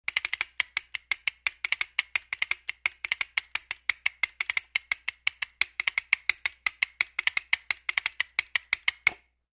Toca Castanet Machine
The Toca T2300 Castanet Machine features two pairs of independently adjustable castanets.